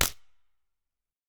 Minecraft Version Minecraft Version 25w18a Latest Release | Latest Snapshot 25w18a / assets / minecraft / sounds / mob / creaking / creaking_freeze3.ogg Compare With Compare With Latest Release | Latest Snapshot
creaking_freeze3.ogg